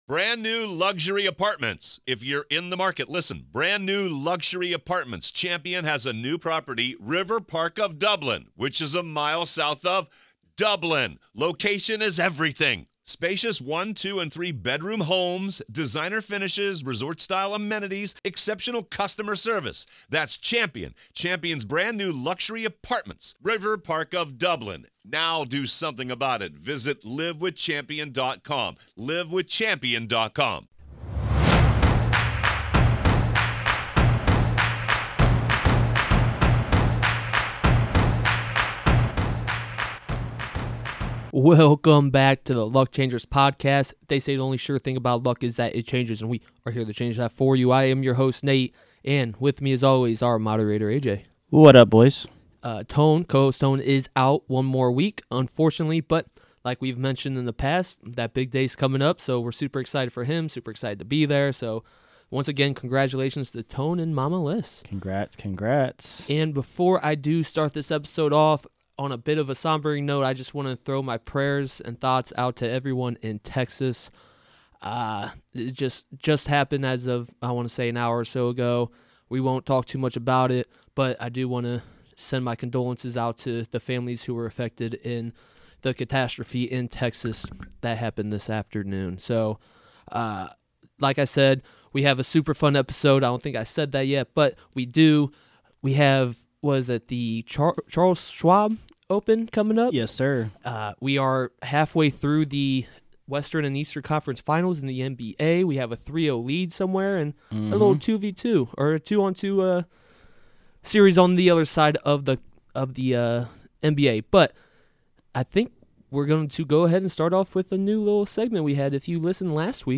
e84 Interview